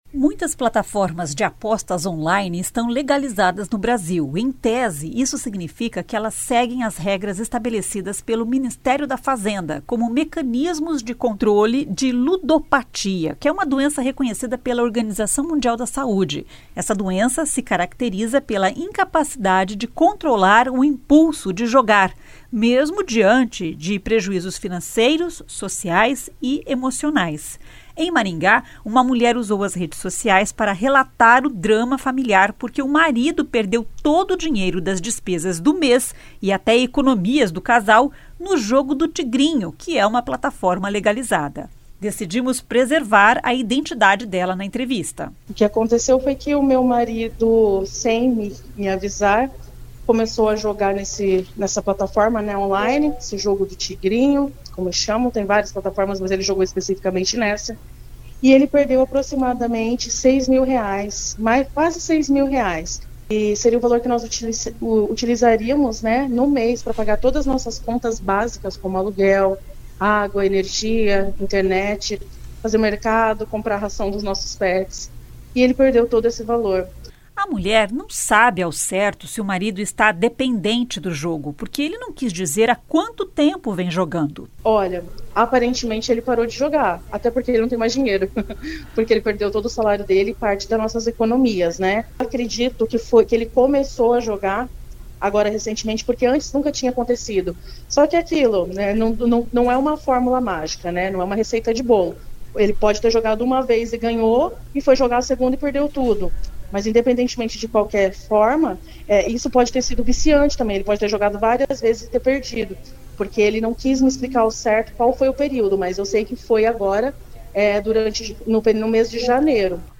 Decidimos preservar a identidade dela na entrevista.